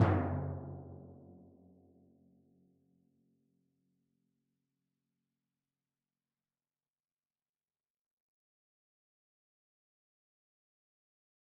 timpani1-hit-v4-rr1-sum.mp3